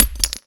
grenade_hit_concrete_03.WAV